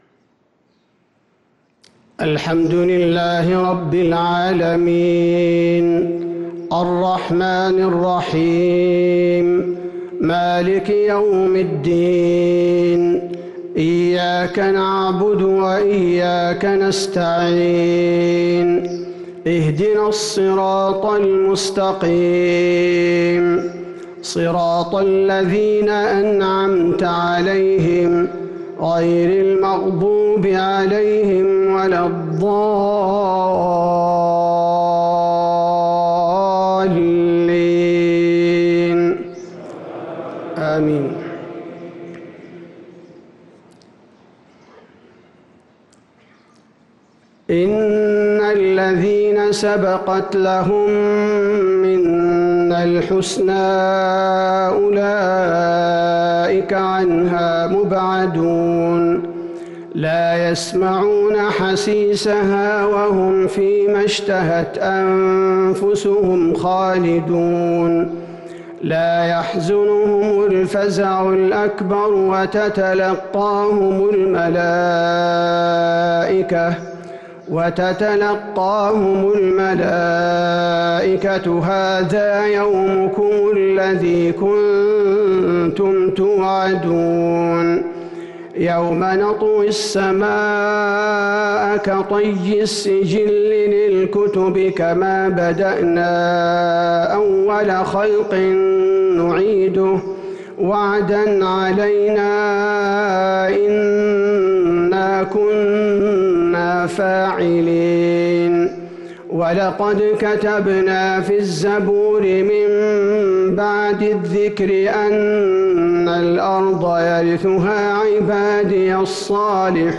صلاة الفجر للقارئ عبدالباري الثبيتي 8 رمضان 1443 هـ